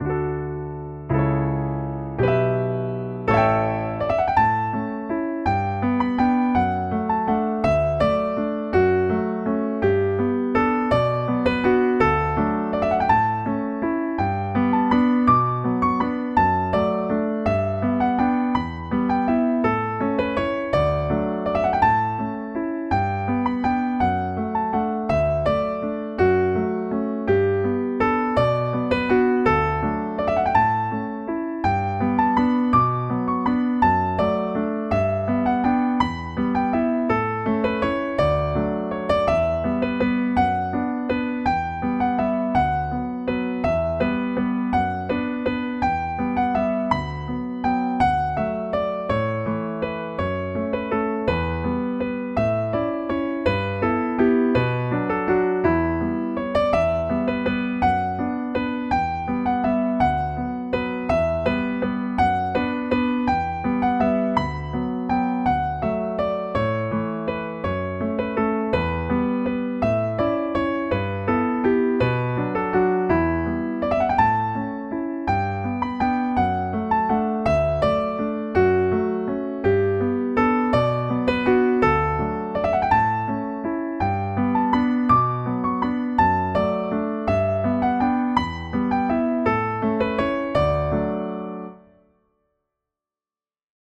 Most of them were written and recorded in just a few hours, and they sound like it.
A little waltz:
Please note that it is the computer playing the piano on these, not me.
Little-Waltz.mp3